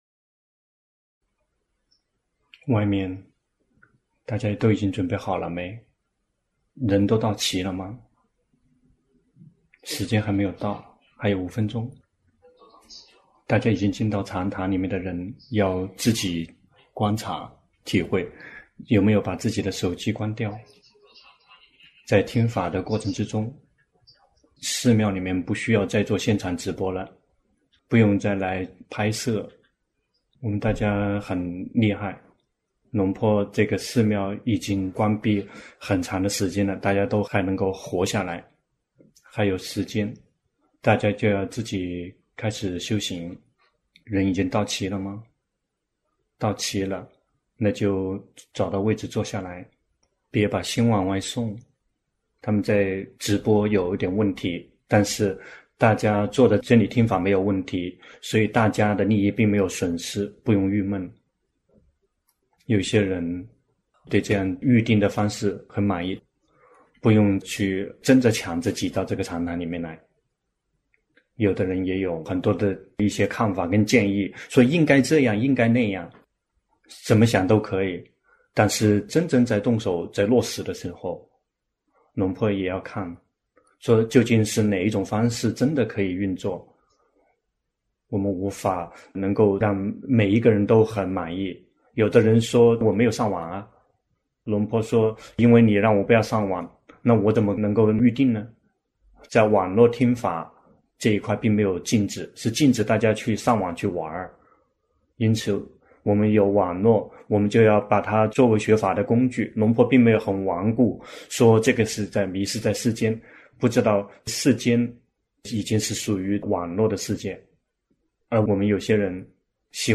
泰國解脫園寺 同聲翻譯